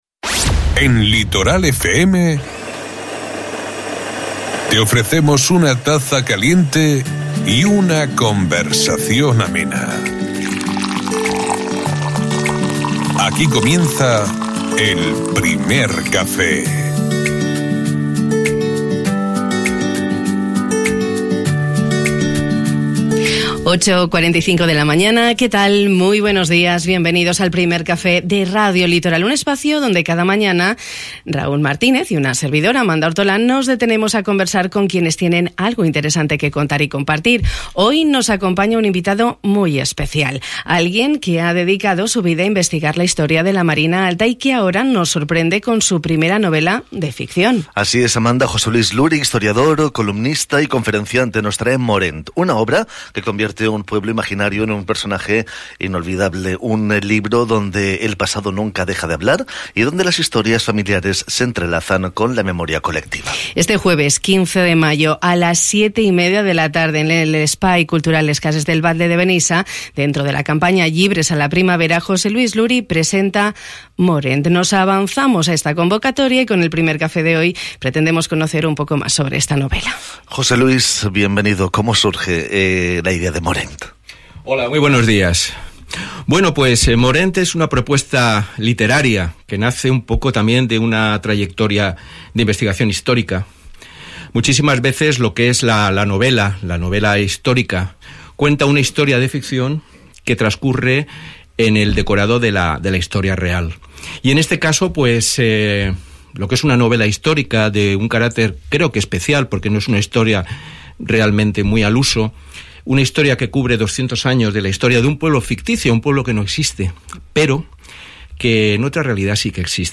Hemos invitado a nuestros estudios